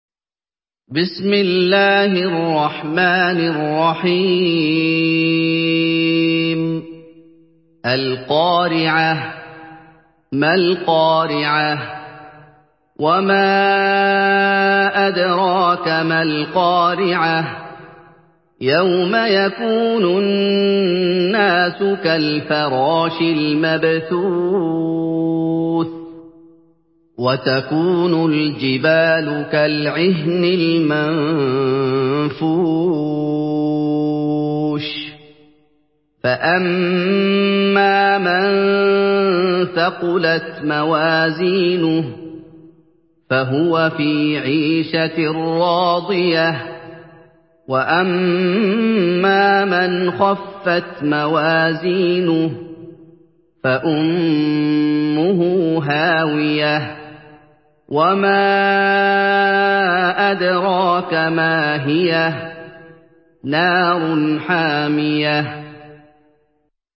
Surah Al-Qariah MP3 by Muhammad Ayoub in Hafs An Asim narration.
Murattal Hafs An Asim